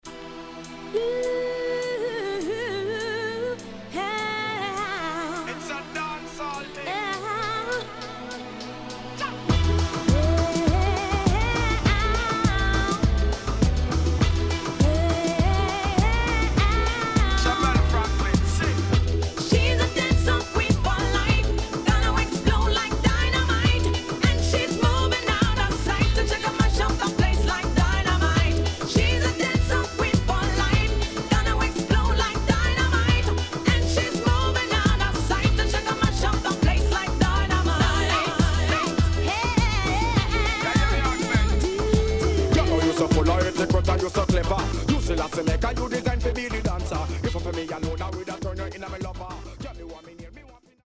CONDITION SIDE A:VG+〜EX-
SIDE A:少しチリノイズ入りますが良好です。